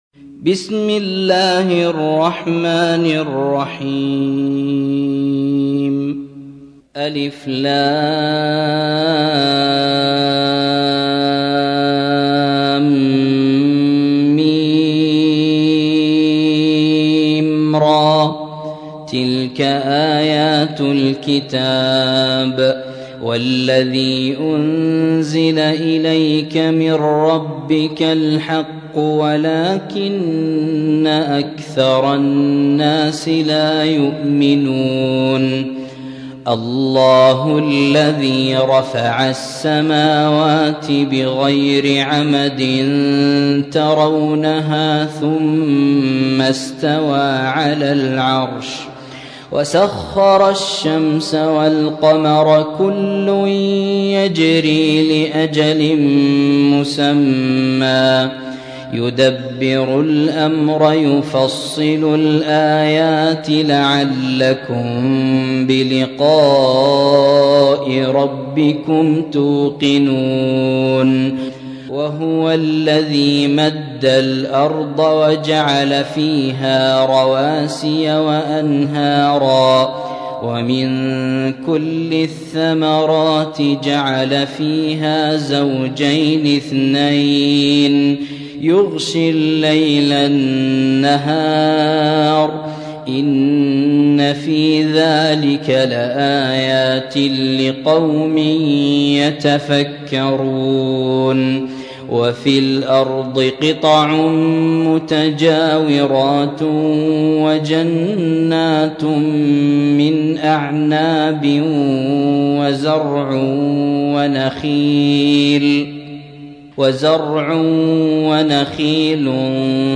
13. سورة الرعد / القارئ